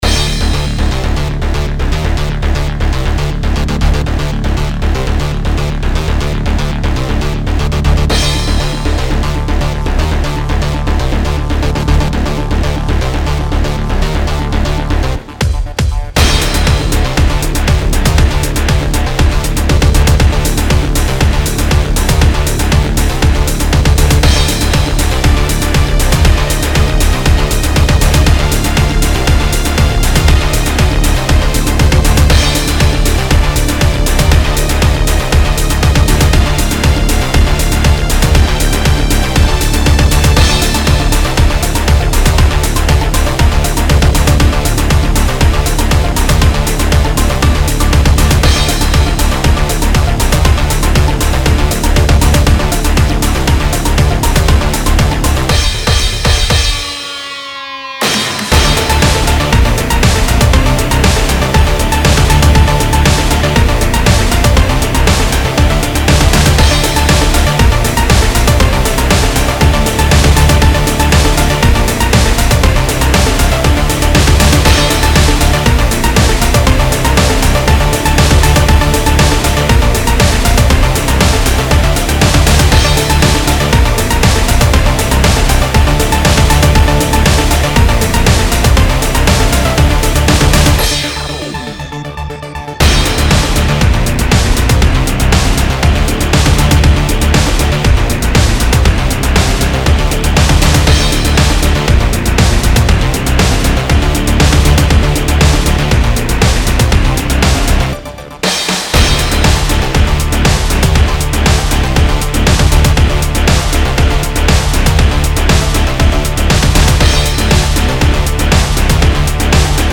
it won't sound too good like bad mixing and mastering
maybe industrial, maybe techno, maybe rock
made with fl studio bpm is 119
Music / Game Music
metallic rock music song fl fl_studio breakbeat big_beat